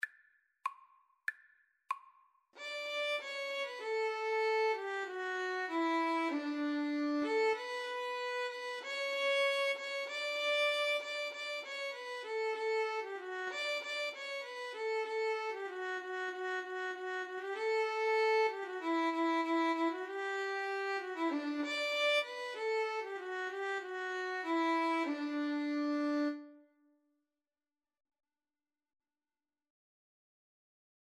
Free Sheet music for Violin-Cello Duet
D major (Sounding Pitch) (View more D major Music for Violin-Cello Duet )
2/4 (View more 2/4 Music)